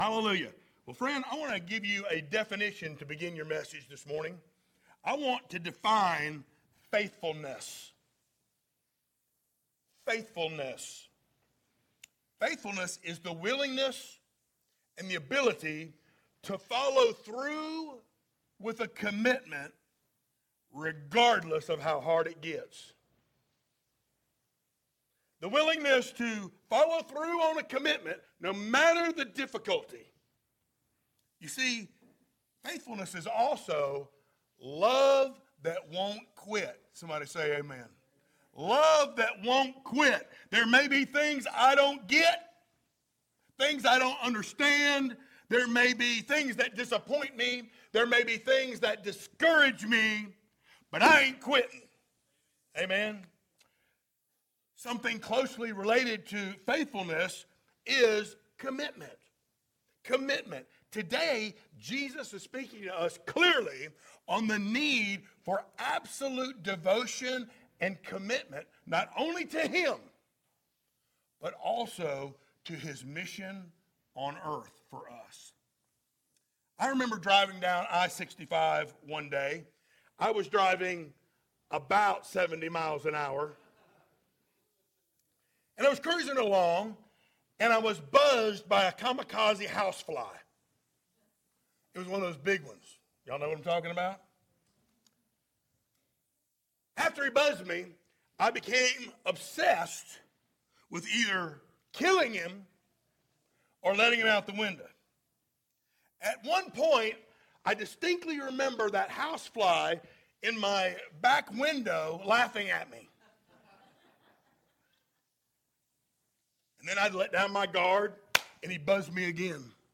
Series: sermons
Matthew 16:21-27 Service Type: Sunday Morning Download Files Notes « The Pleasure Of God’s Presence What’s It Gonna’ Take?